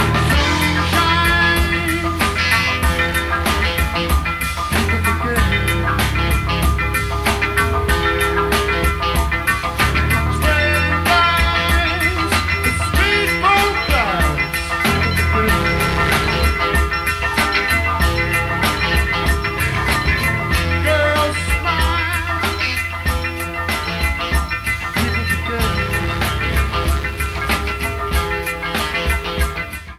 Pre-FM Radio Station Reels
Needs remastering.